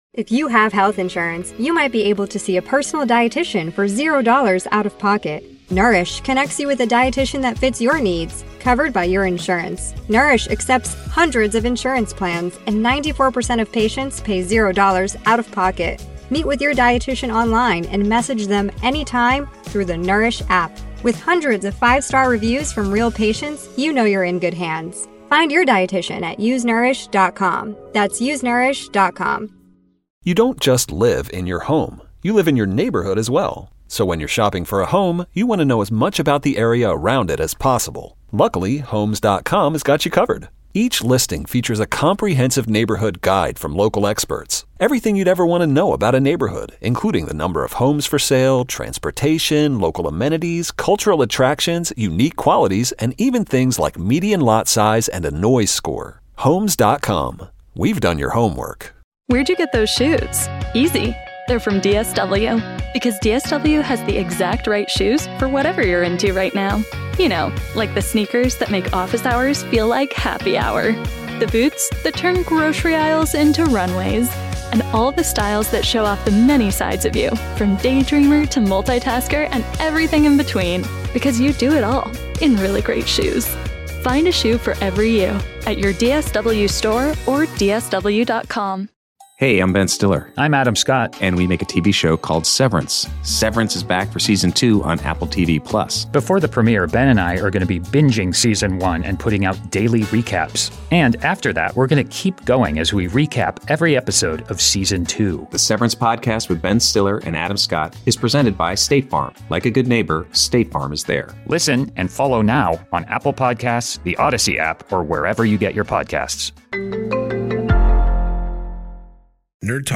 As heard on 107.7 The End in Seattle